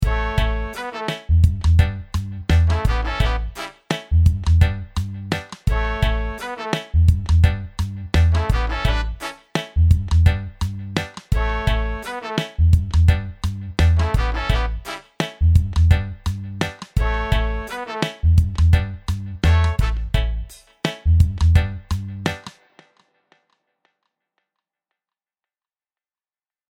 Ich finde die Horns echt gelungen. Hier mal ein kleines Beispiel, Sound direkt aus dem Channelstrip-Preset. 3Piece-Section Chicago.
Höchste Note Trompete Mittelnote Sax und tiefe Note Posaune.